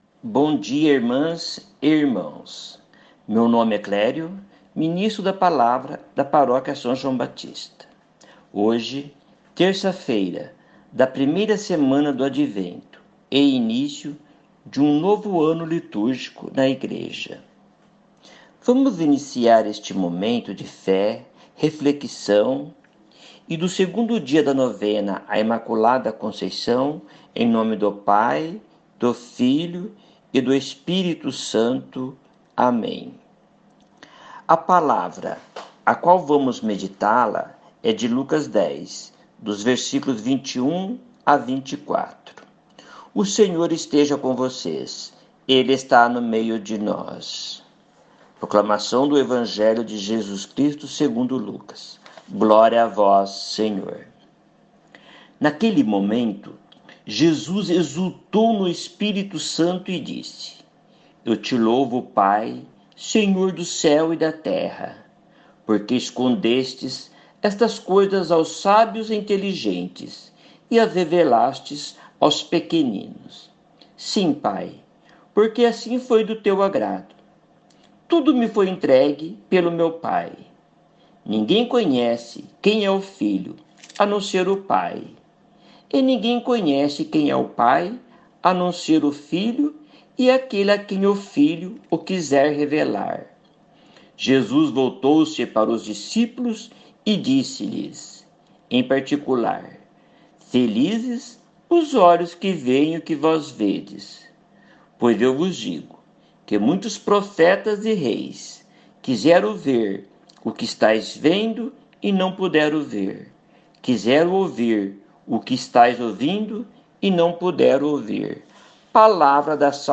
Evangelho do Dia